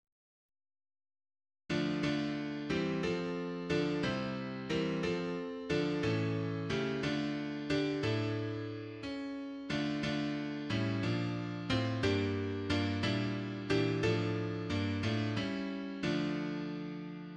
HYMN: Christopher L Webber